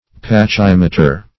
Pachymeter \Pa*chym"e*ter\, n.